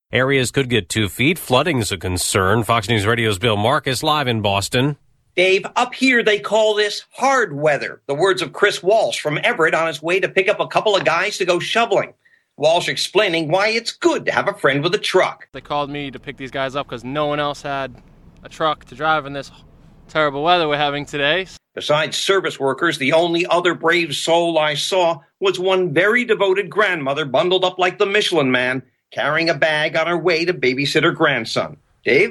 LIVE AT 9AM….